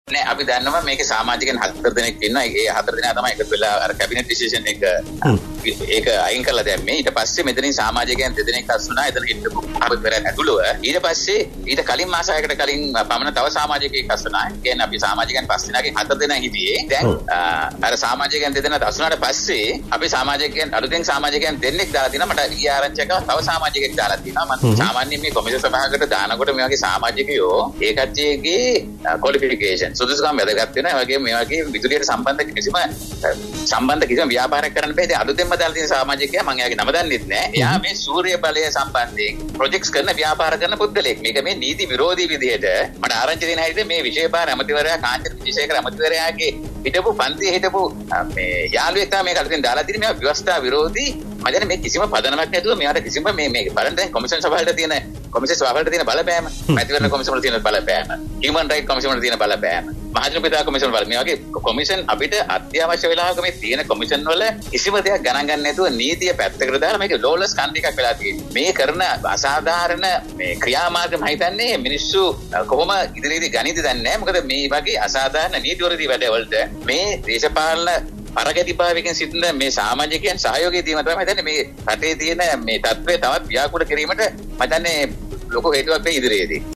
මේ අතර අද පෙරවරුවේ විකාශය වූ සිද්ධමුල්ල කාලින වැඩසටහනට විදෙස්ගතව සිටින ජනක රත්නායක මහතා සම්බන්ධ වුණා.
මේ ඒ සම්බන්ධයෙන් තවදුරටත් අදහස් පල කළ මහජන උපයෝගිතා කොමිසමේ සභාපති ජනක රත්නායක මහතා.